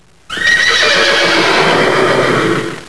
horselaug2.wav